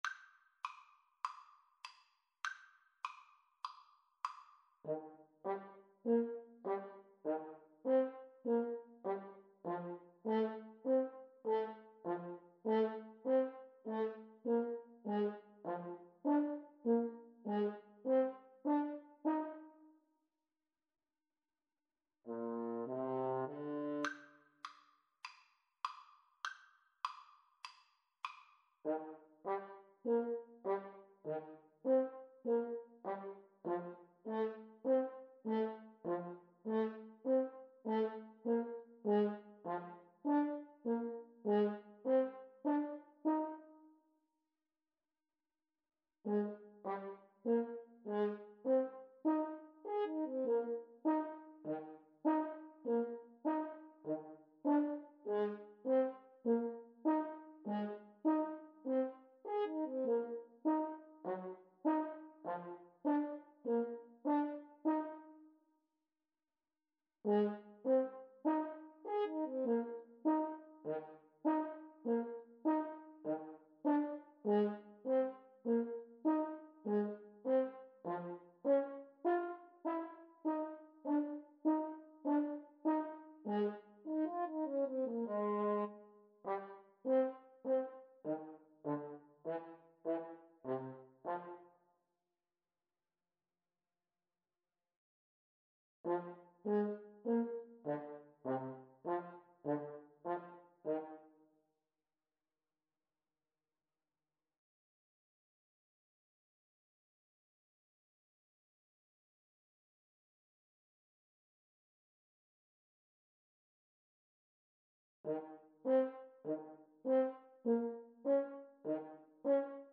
Eb major (Sounding Pitch) (View more Eb major Music for Clarinet-French Horn Duet )
Lightly = c. 100
4/4 (View more 4/4 Music)